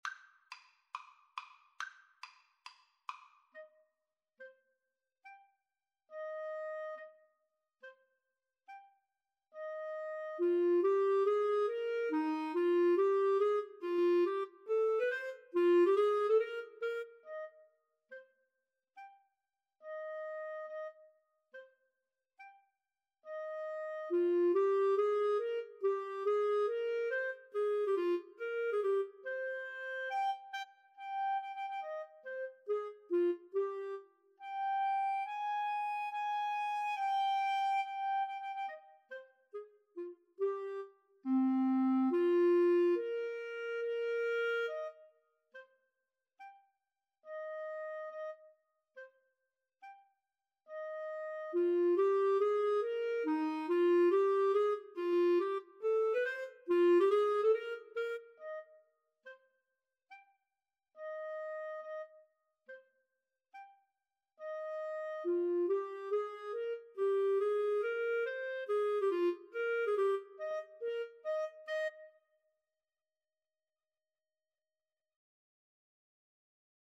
Tempo di marcia =140